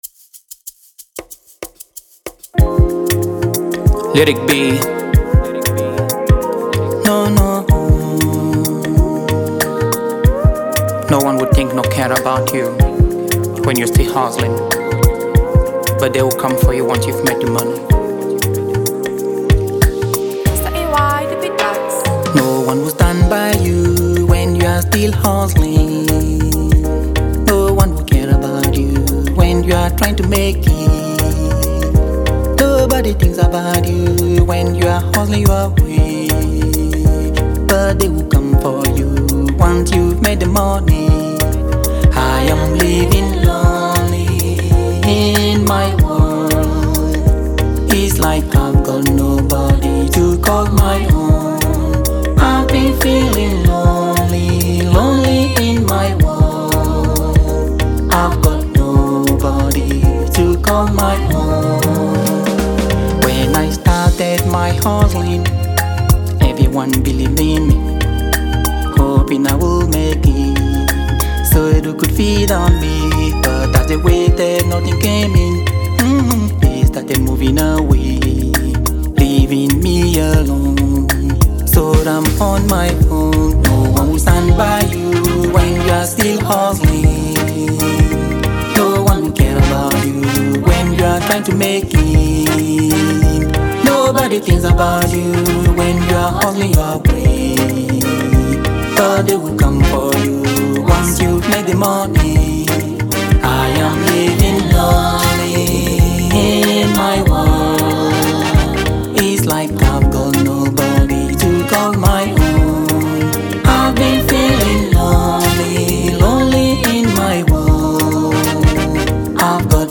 better known as the sensational Afrobeat artist